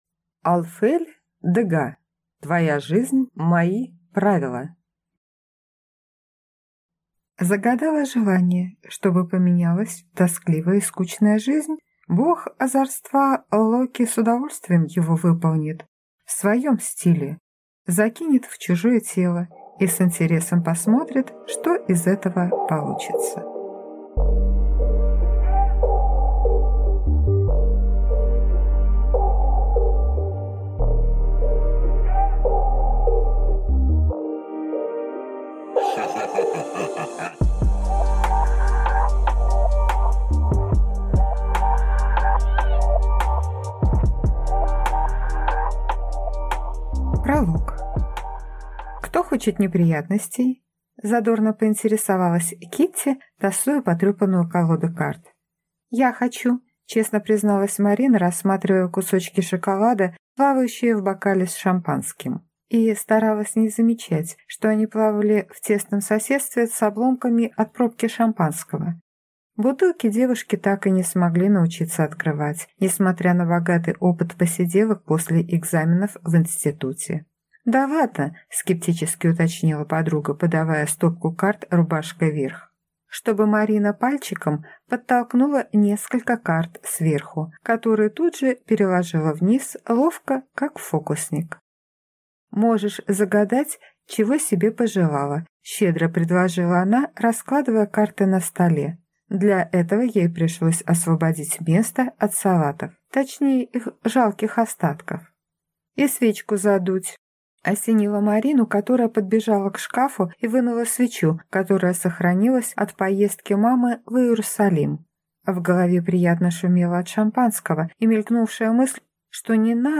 Аудиокнига Твоя жизнь – мои правила | Библиотека аудиокниг